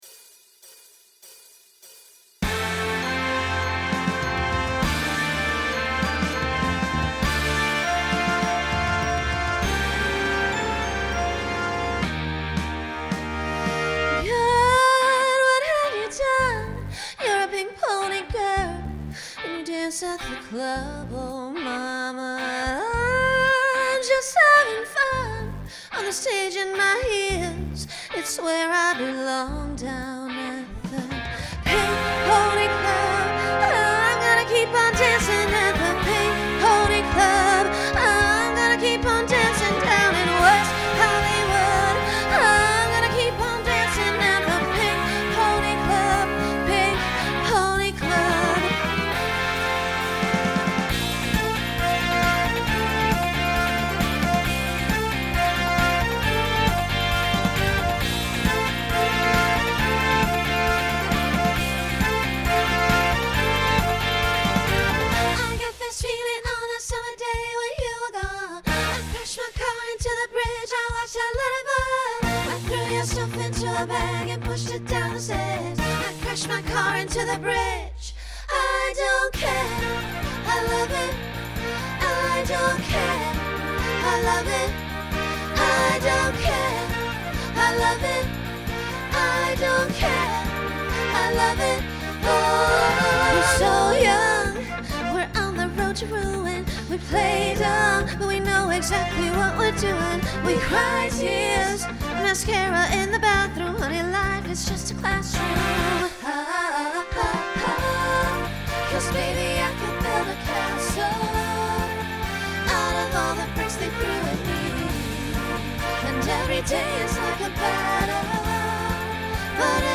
Genre Pop/Dance
Solo Feature Voicing SSA